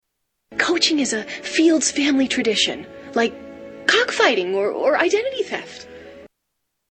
Tags: Movies The Comebacks The Comebacks Clips Comedy David Koechner